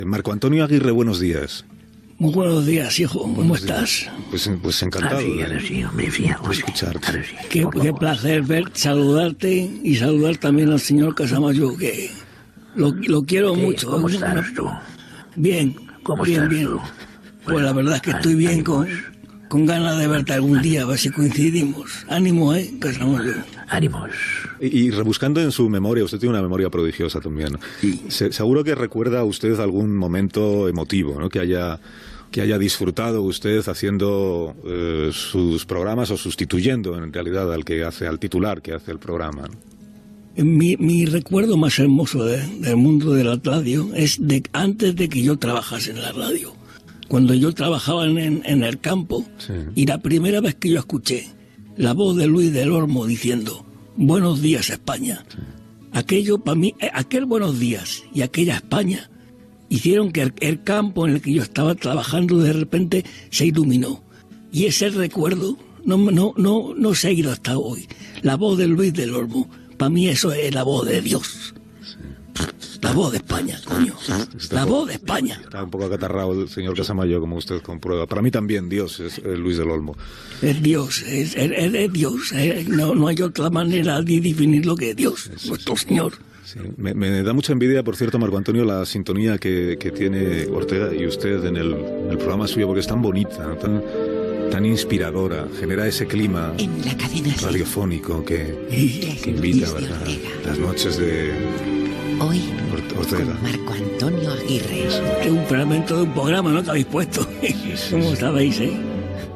Homenatge a la ràdio en temps de Covid. Fragments d'entrevistes a:
Info-entreteniment